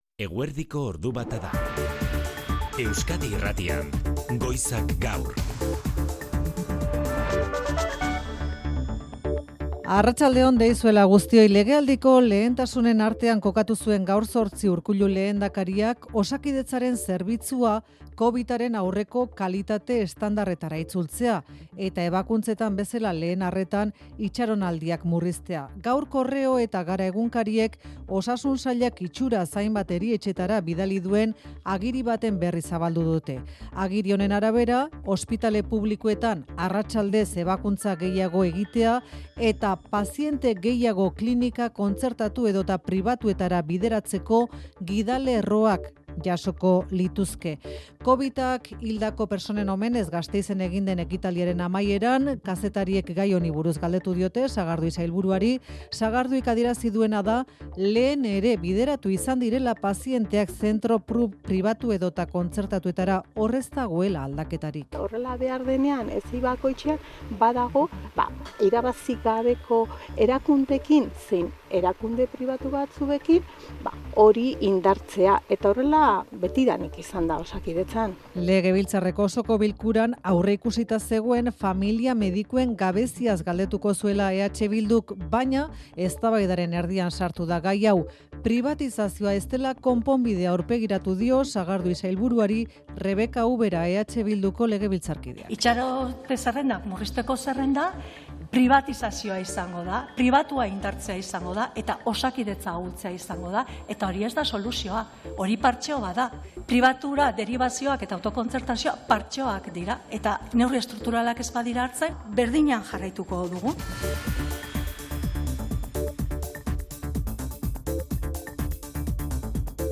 Eguerdiko albistegia.